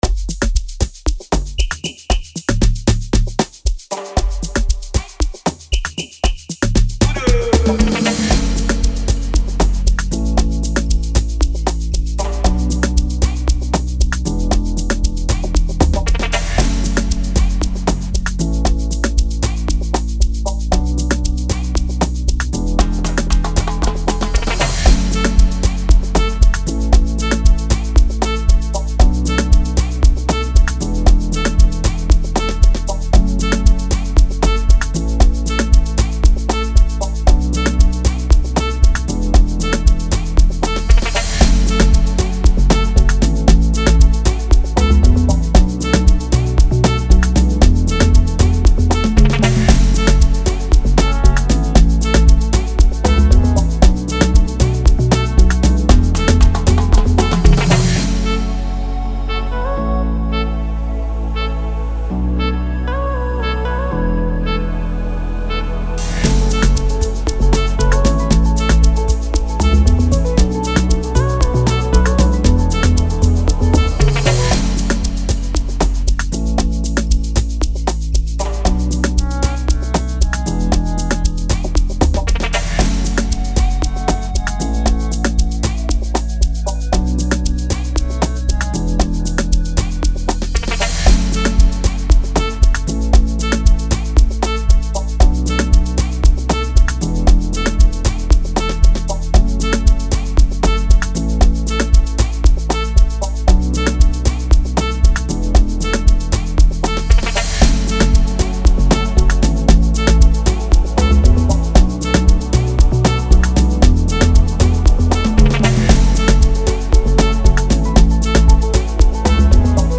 afrobeats instrumental